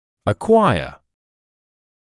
[ə’kwaɪə][э’куайэ]получать; приобретать